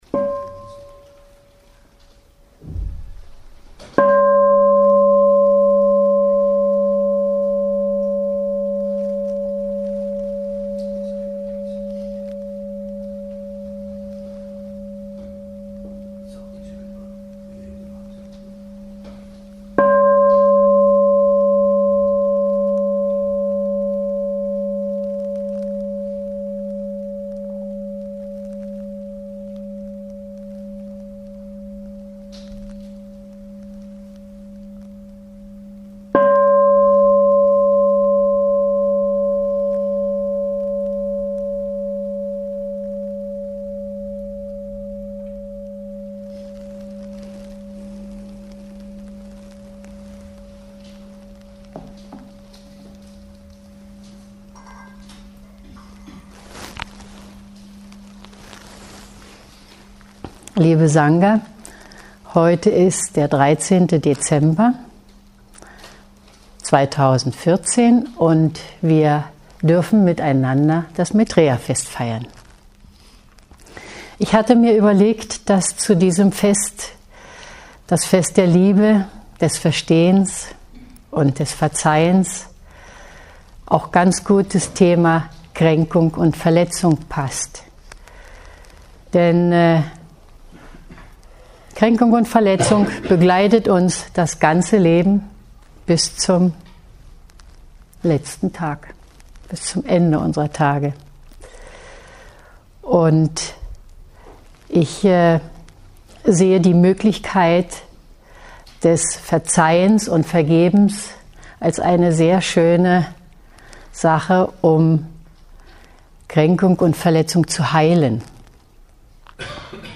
Vorträge
Diese Seite enthält Vorträge, geführte Meditationen, Tiefenentspannungen und mantrische Lieder die im Rahmen von Veranstaltungen der GAL aufgenommen wurden.
Wenn nicht anders vermerkt ist, stammen die Vorträge von Tagen der Achtsamkeit.